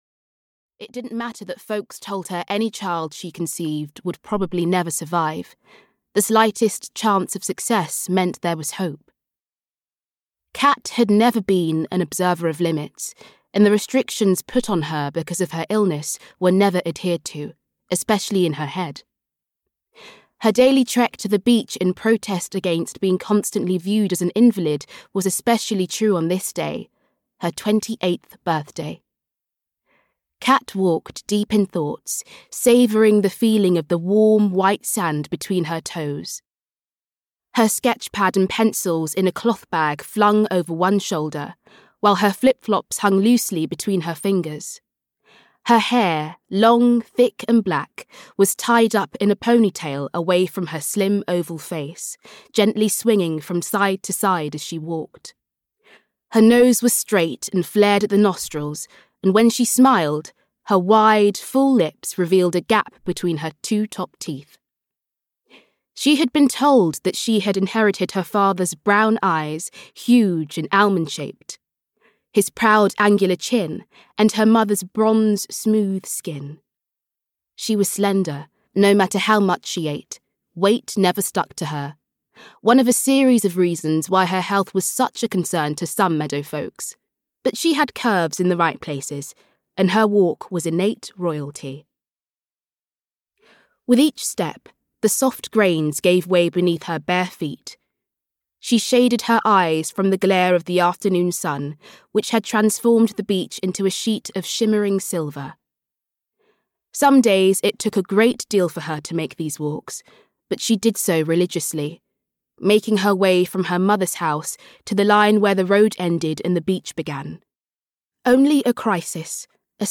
Swimming with Fishes (EN) audiokniha
Ukázka z knihy